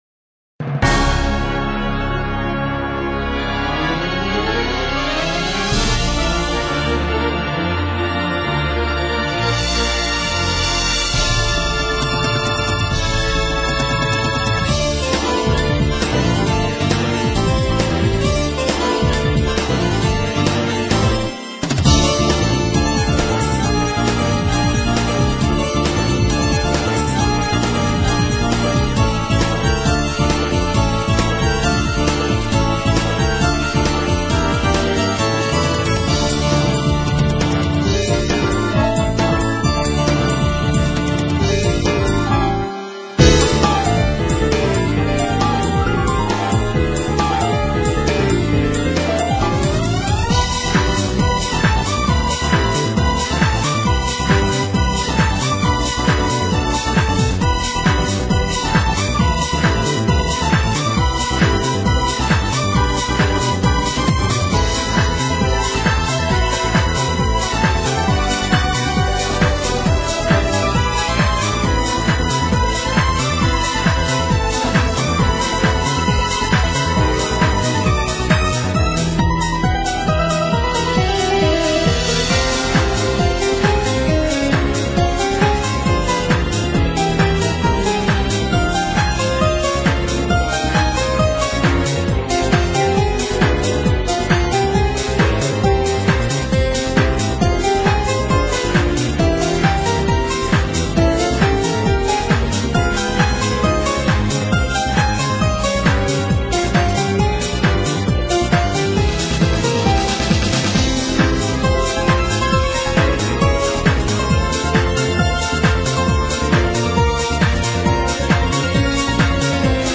arranged version